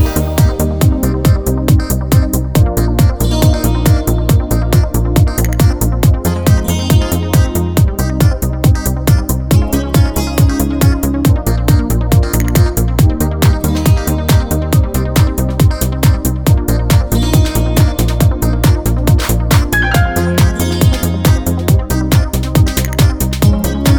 Dance Version With No Backing Vocals Dance 3:55 Buy £1.50